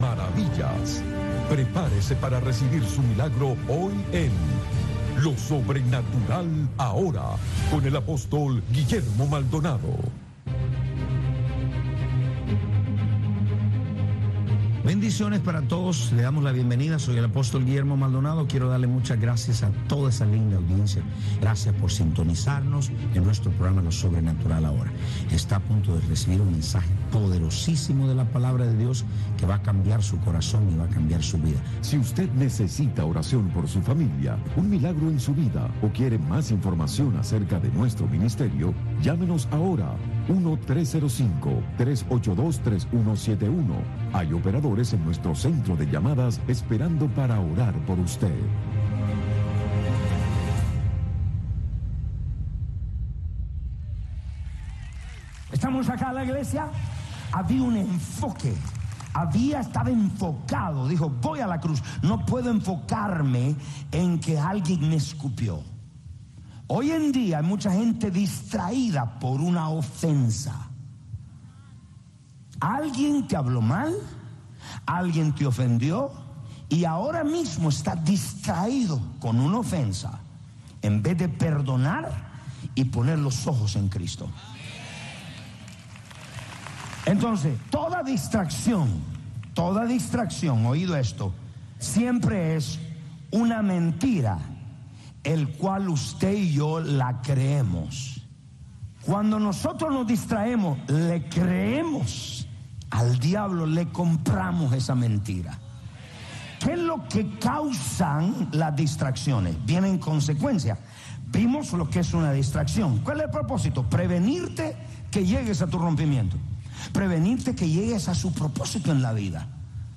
Radio Martí te presenta todos los sábados y domingos entre 6 y 8 de la mañana el bloque religioso “La Religión en Martí” en donde te presentaremos diferentes voces de académicos, pastores y hombres de fe que te traerán la historia y la palabra esperanzadora del señor.